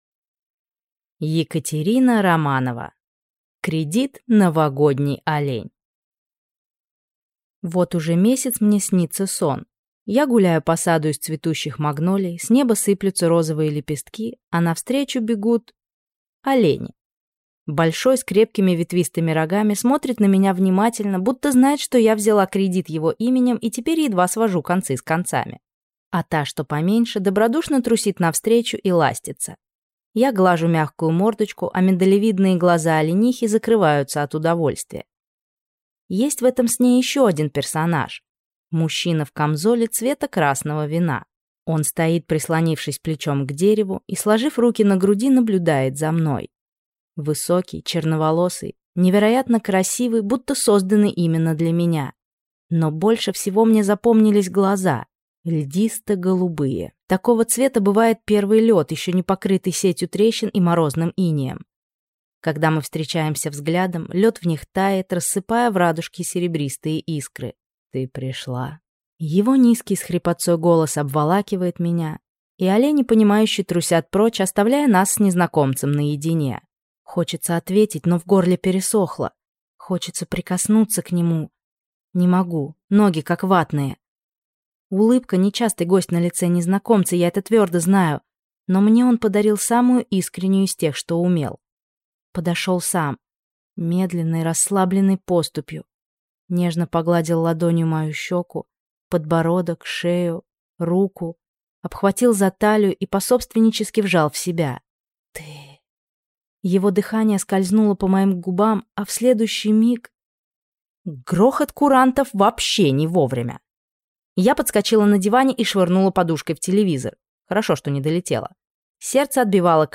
Аудиокнига Кредит «Новогодний олень» | Библиотека аудиокниг